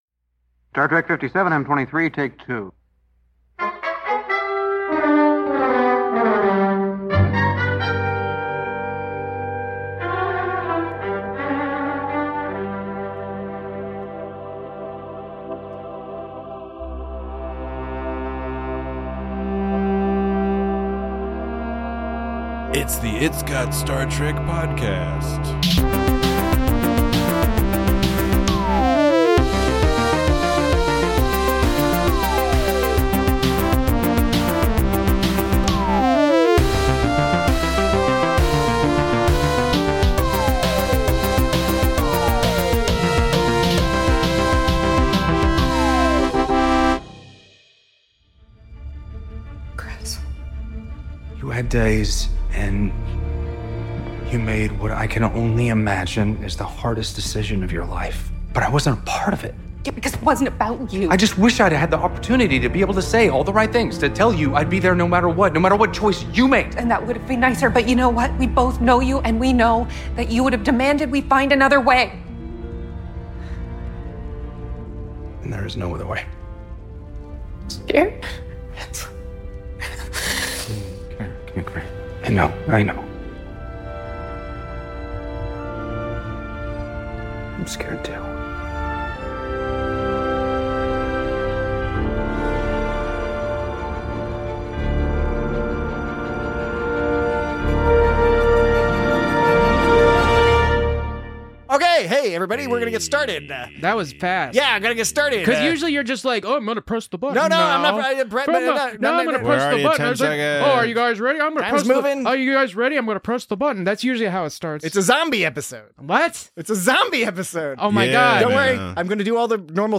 Join your moss-loving hosts as they discuss genre-hoppping plots, hats on hats on hats, and continued additions to the rapidly expanding (if still frustratingly one-dimensional) corpus of Gornlore. Also, there are lists and doody or no doodies and silly voices and listener mail and, perhaps, a digression or two.